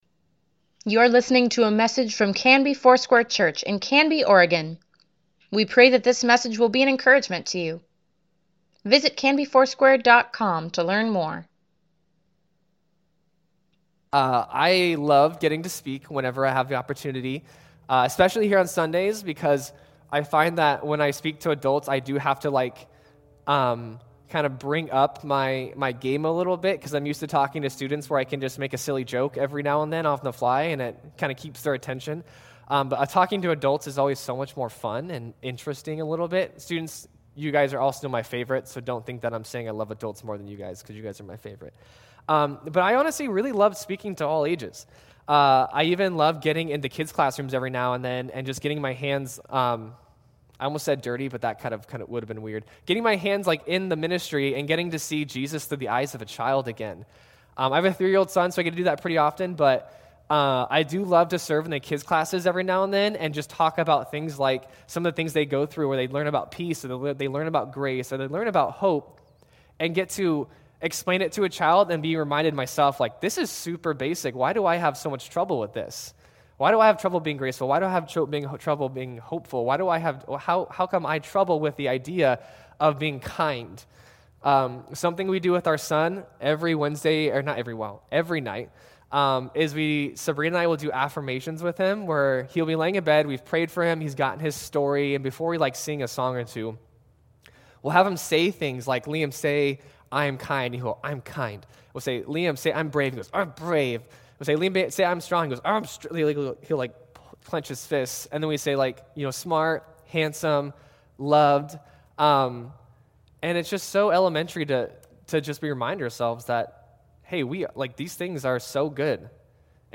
Weekly Email Water Baptism Prayer Events Sermons Give Care for Carus Emmanuel - God With Us November 29, 2020